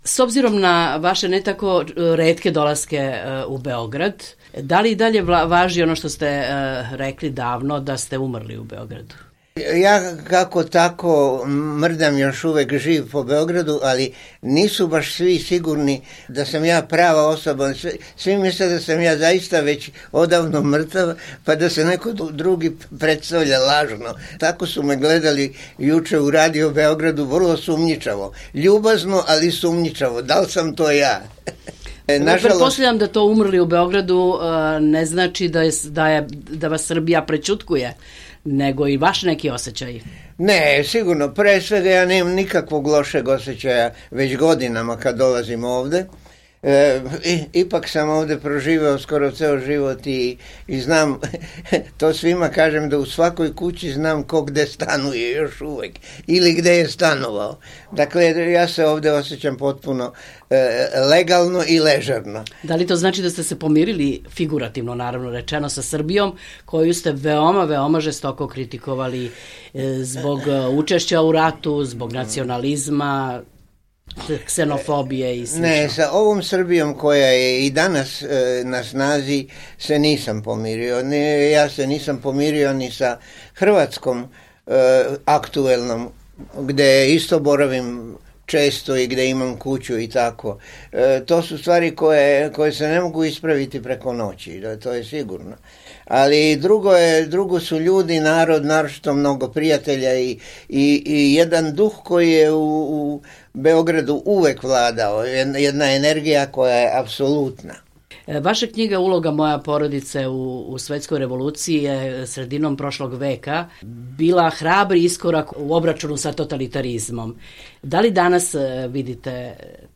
Intervju nedelje: Bora Ćosić